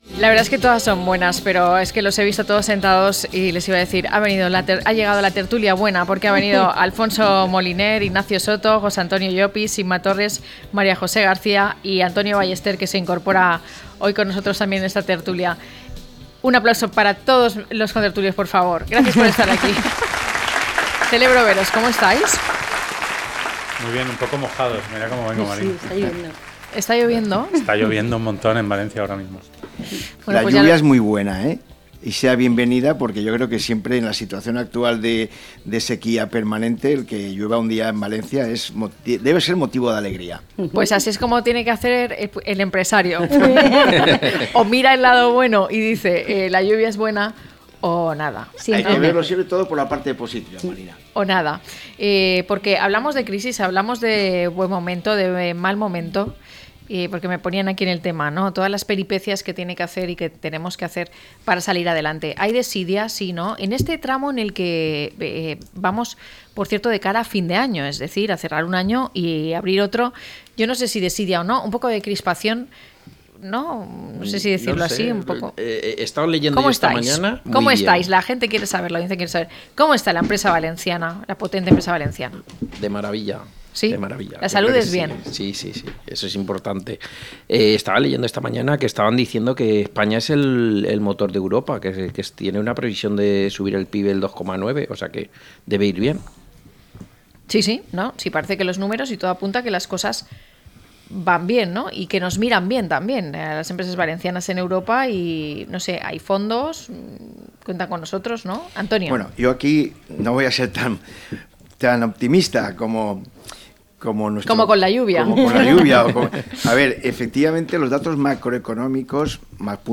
Tertulia empresarial